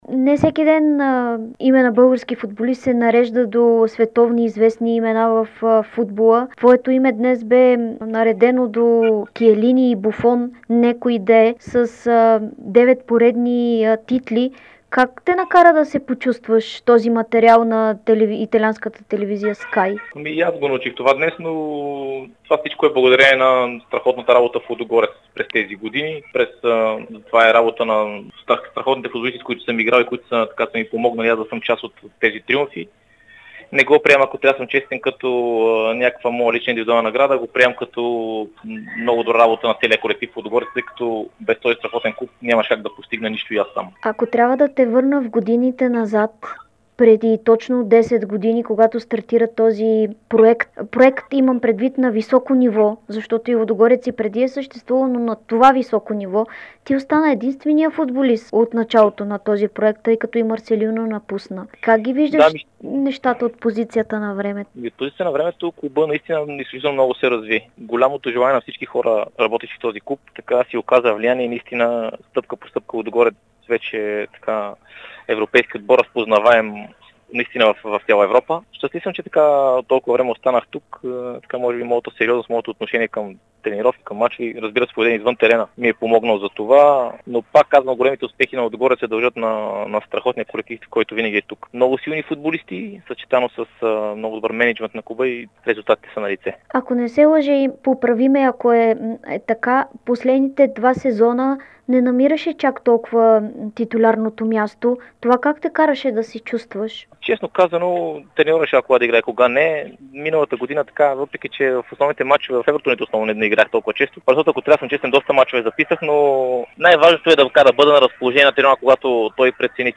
Капитанът на Лудогорец Светослав Дяков даде специално интервю за Дарик радио и dsport, в което бе категоричен, че целта му през новия сезон е разградчани да спечелят десета поредна титла, с което да сложат на екипа си специалната звезда, която индикира постижението.